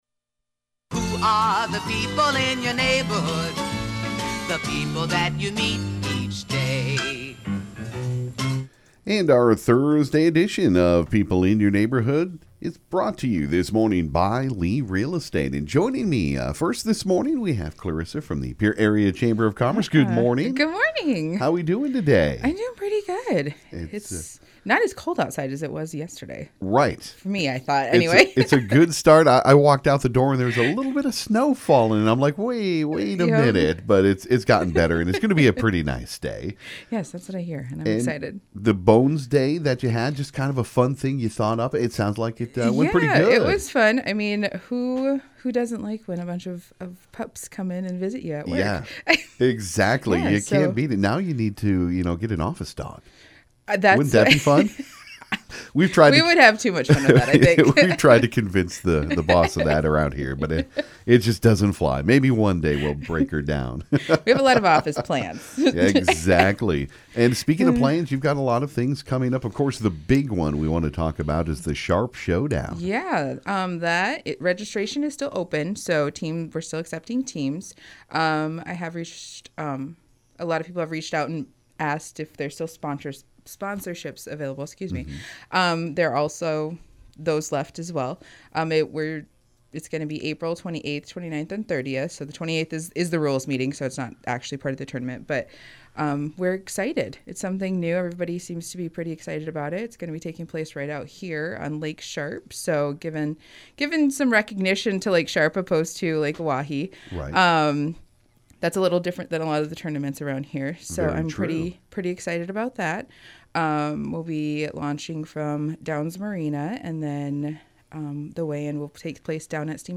This morning on KGFX we had a couple of great guests for People In Your Neighborhood.